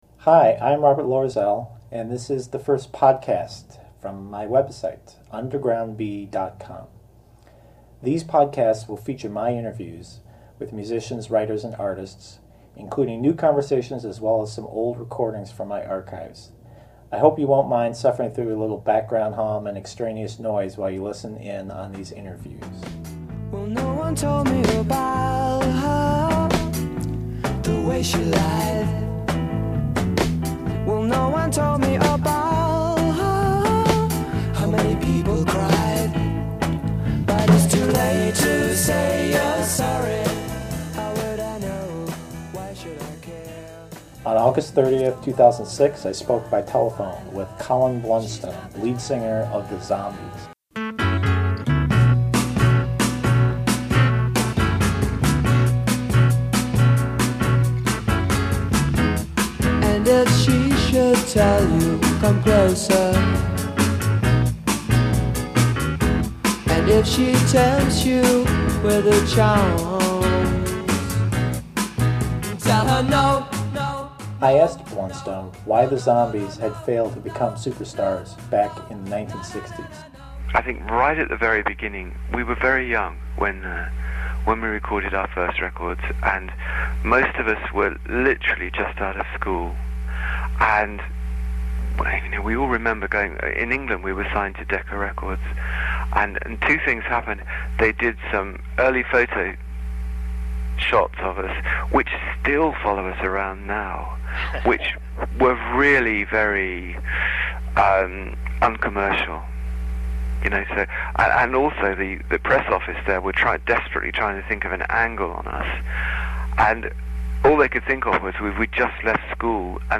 Interview with Colin Blunstone of the Zombies